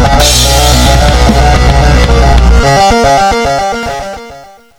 Reduce most sounds to 8bit, up their frequency